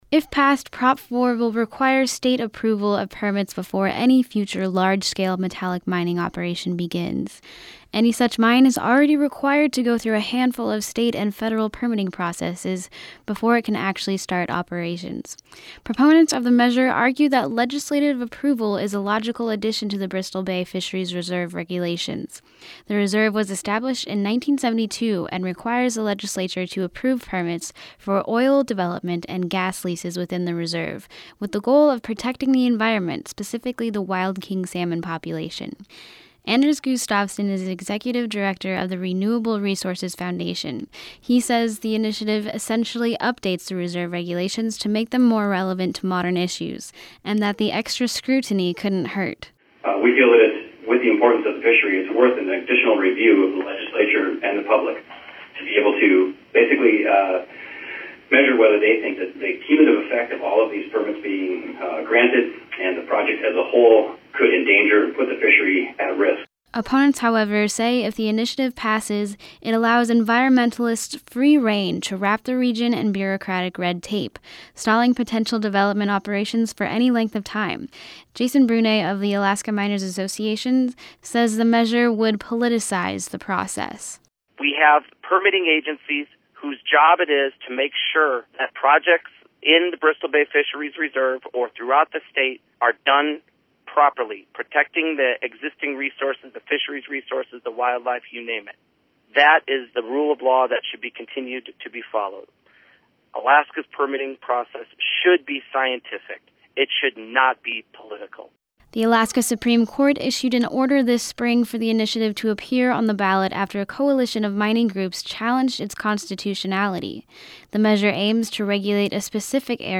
Lt. Gov. Mead Treadwell hosted an initiative hearing on Proposition 4 Thursday in Ketchikan.